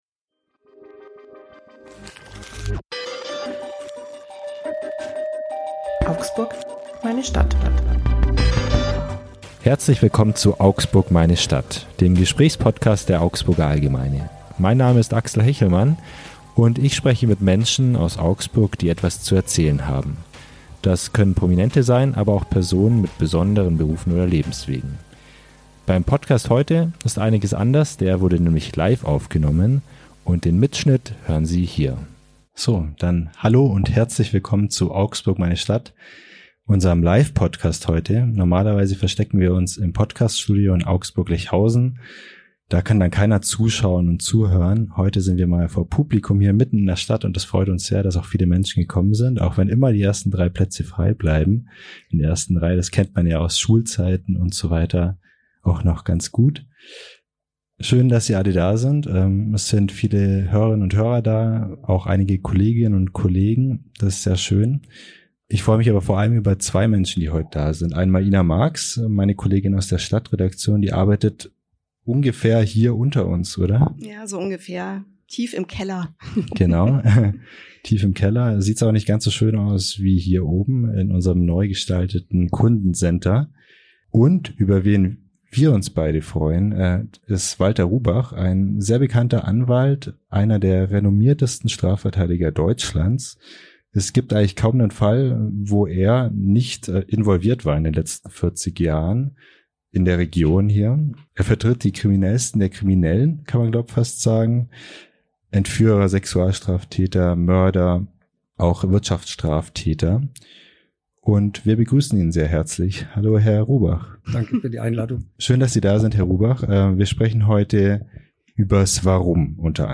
Für diese Folge hat unsere Redaktion den Podcast vom Aufnahmestudio auf die Bühne verlagert.